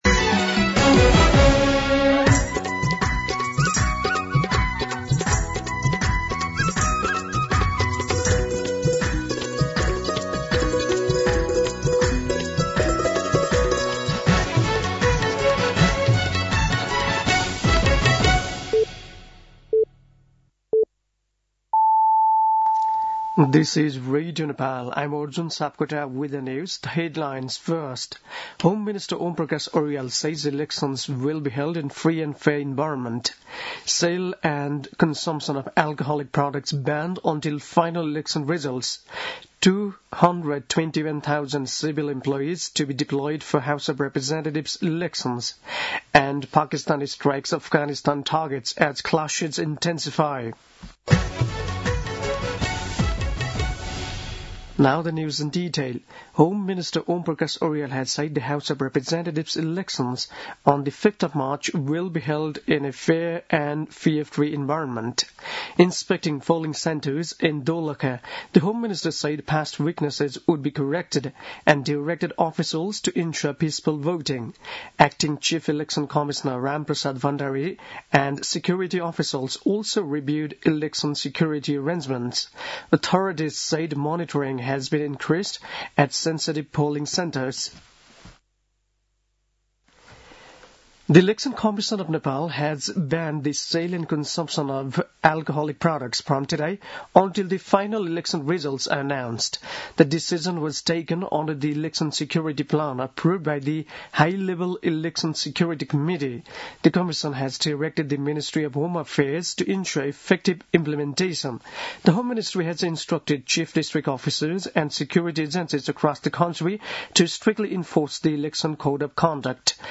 दिउँसो २ बजेको अङ्ग्रेजी समाचार : १५ फागुन , २०८२